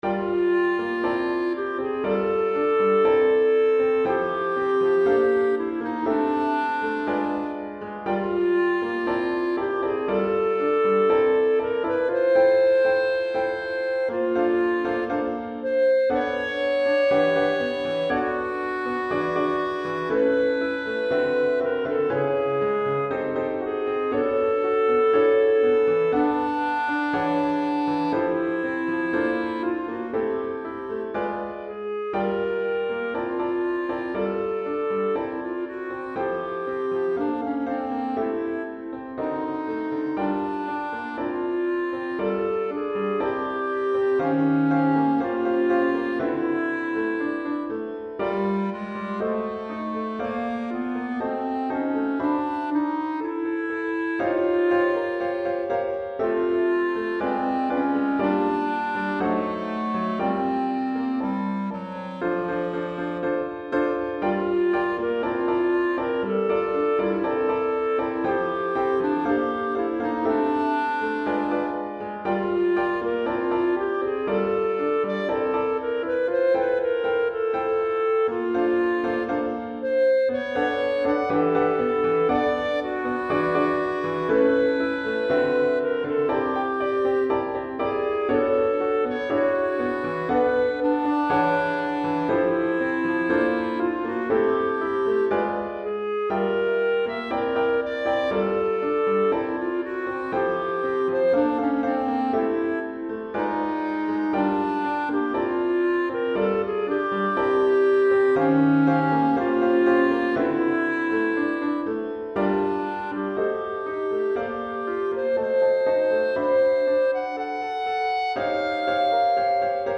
Clarinette en Sib et Piano